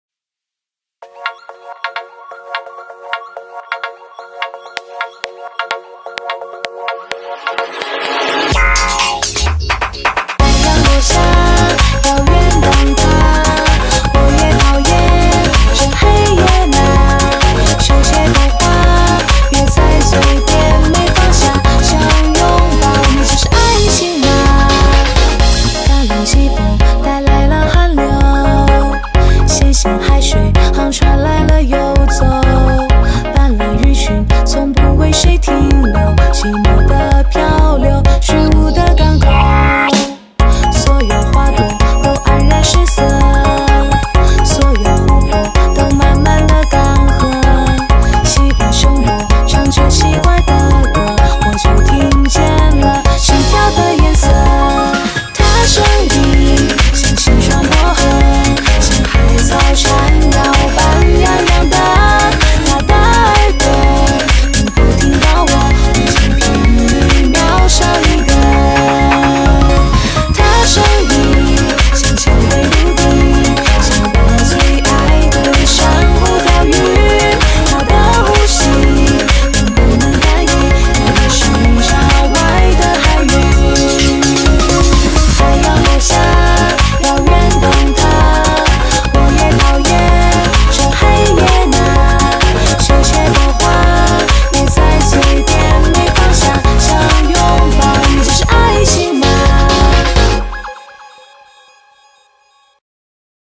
BPM128
Audio QualityCut From Video